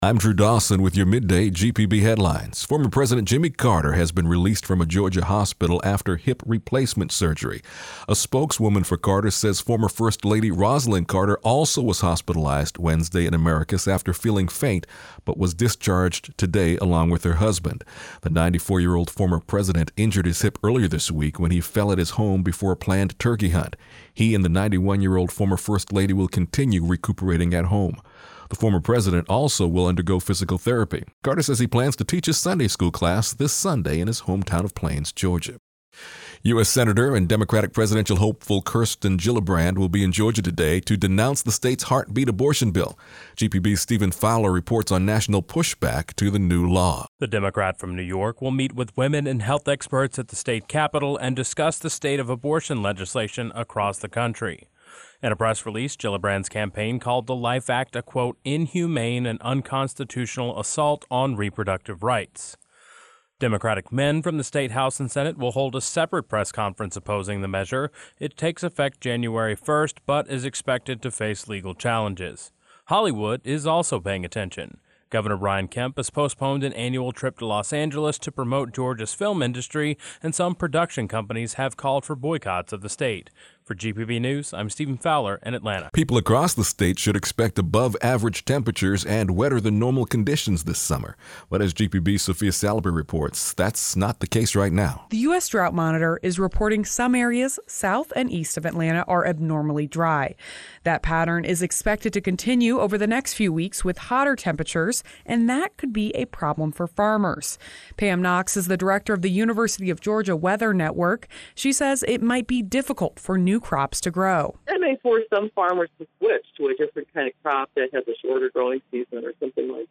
GPB Midday Headlines For Thursday May 16, 2019